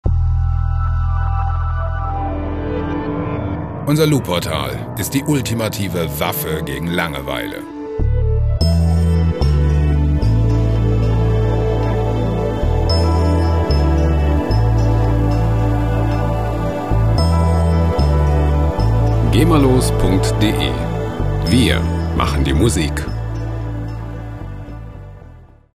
IDM Loops
Musikstil: Ambient
Tempo: 56 bpm